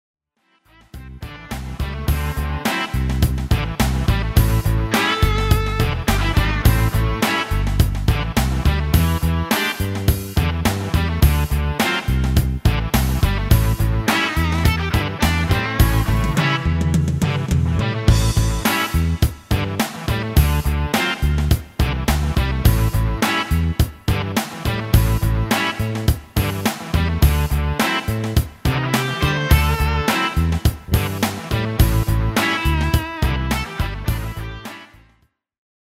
Demo/Koop midifile
Genre: Pop & Rock Internationaal
- Géén tekst
- Géén vocal harmony tracks